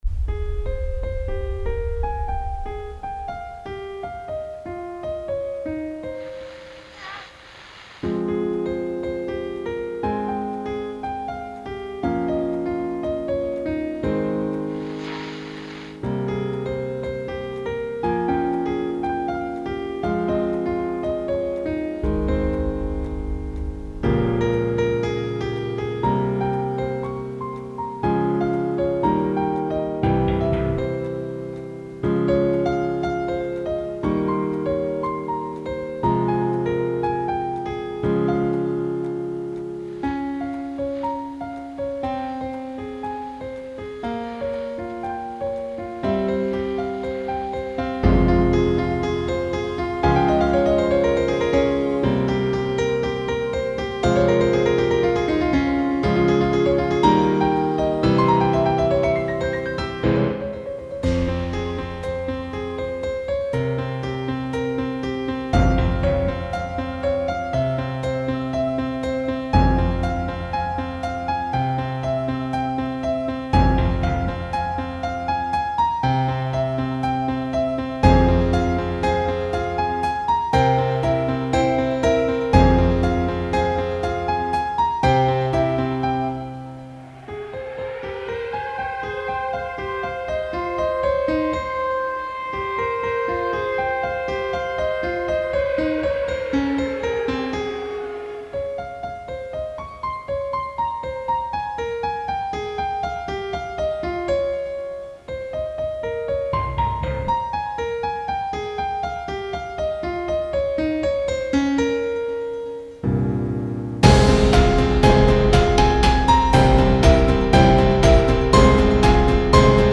Прикольная пианинная тема.